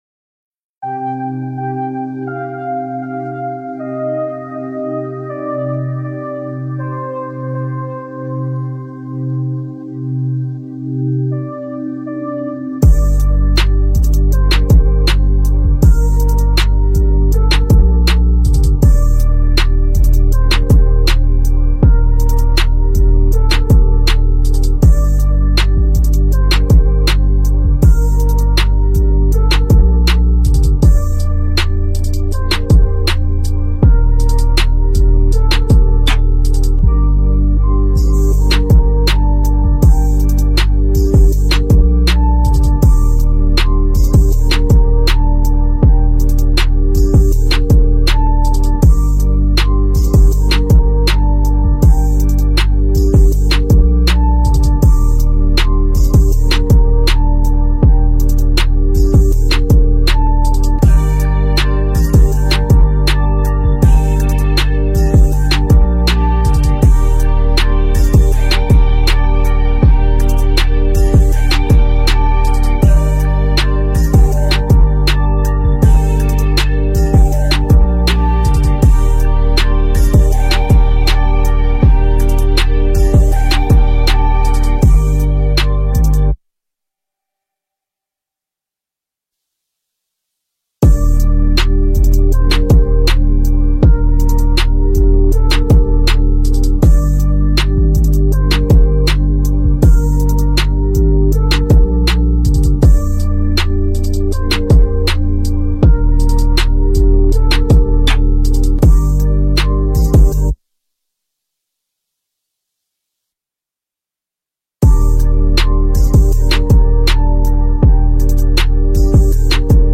بیت اصلی و آورجینال آهنگ معروف